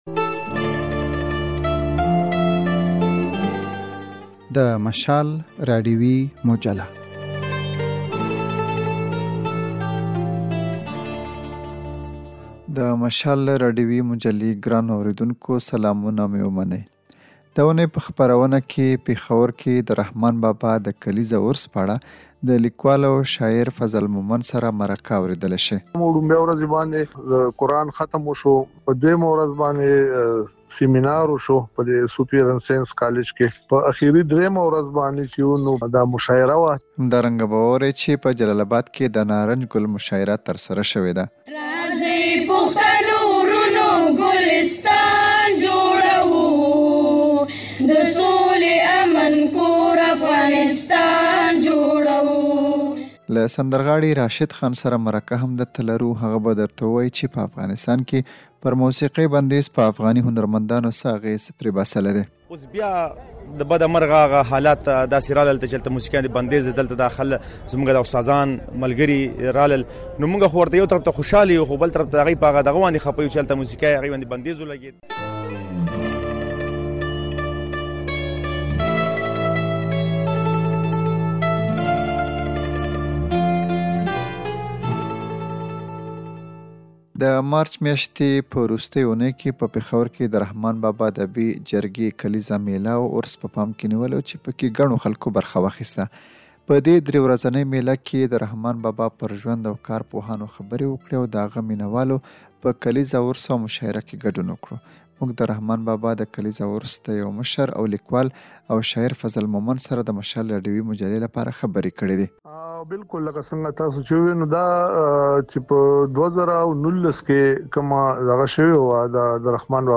دا اوونۍ په مشال راډیويي مجله کې په پېښور کې د رحمان بابا کلیزه عرس پر مهال د مشاعرې په اړه مرکه، په جلال اباد کې د نارنج ګل کلیزه مشاعرې په اړه راپور